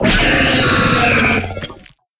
Cri de Chef-de-Fer dans Pokémon HOME.